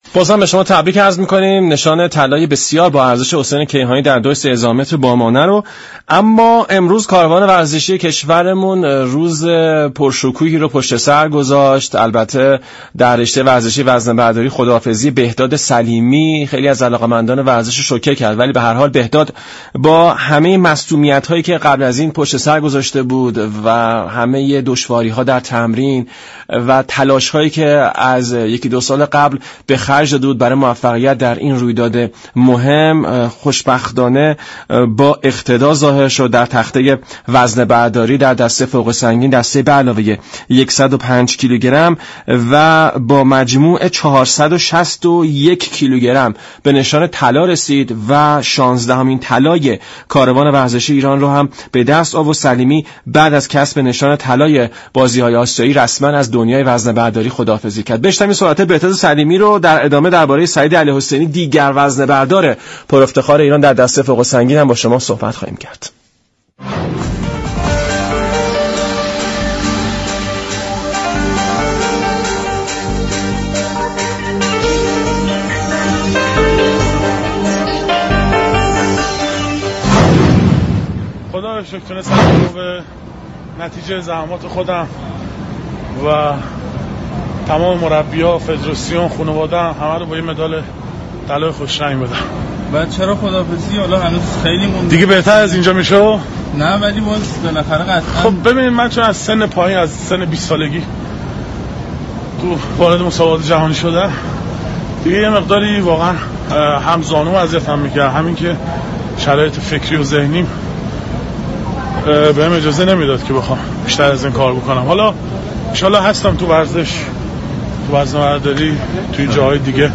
برنامه «ورزش ایران» در قالب یك گزارش با بهداد سلیمی ورزشكار نام آور ایرانی به گفت و گو پرداخته است.